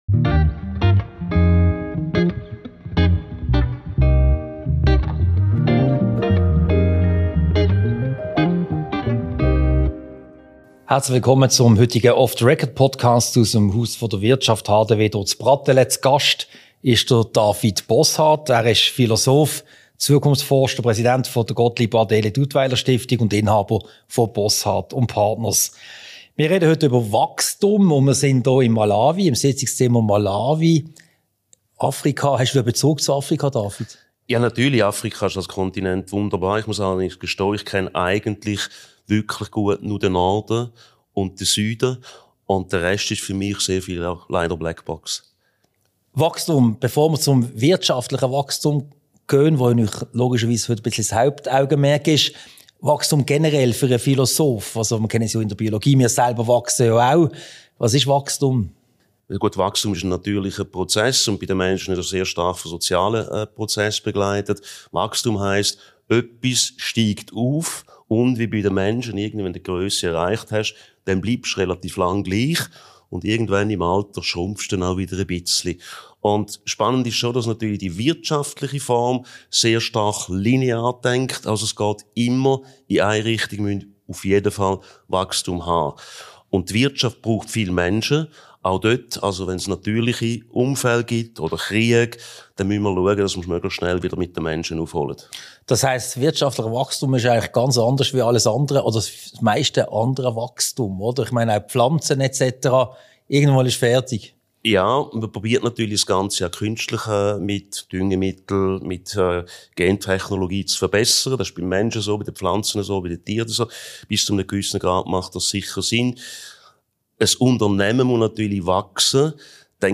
Ein Gespräch über Wachstum, die Globalisierung und die Rolle der Schweiz in dieser Welt.
Diese Podcast-Ausgabe wurde als Video-Podcast im Sitzungszimmer Malawi im Haus der Wirtschaft HDW aufgezeichnet.